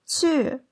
「去 qù」の声調は、第四声です。
子音と母音を合わせた「qù」は、「チュィー」のような音になります。